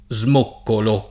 smoccolare
vai all'elenco alfabetico delle voci ingrandisci il carattere 100% rimpicciolisci il carattere stampa invia tramite posta elettronica codividi su Facebook smoccolare v.; smoccolo [ @ m 0 kkolo o @ m 1 kkolo ] — - 1 - e - 0 - come moccolo